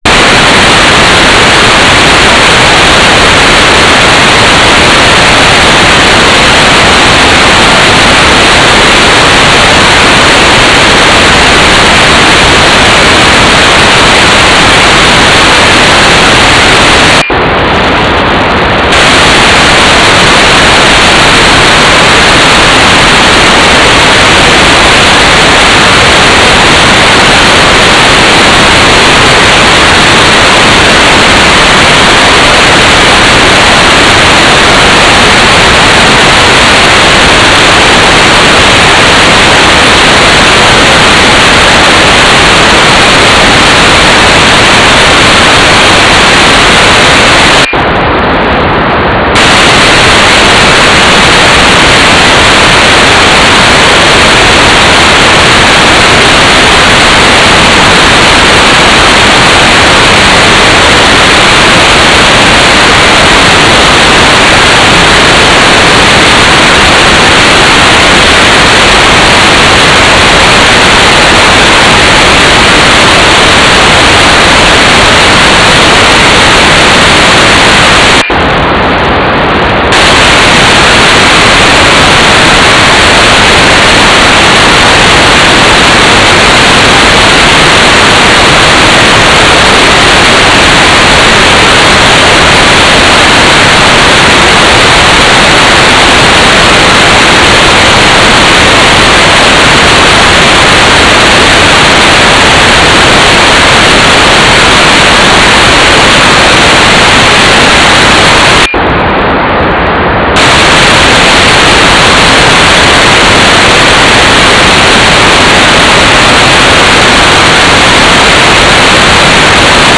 "transmitter_description": "Mode U - GMSK4k8 TLM (Mobitex)",
"transmitter_mode": "GMSK",